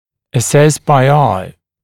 [ə’ses baɪ aɪ][э’сэс бай ай]оценивать на глаз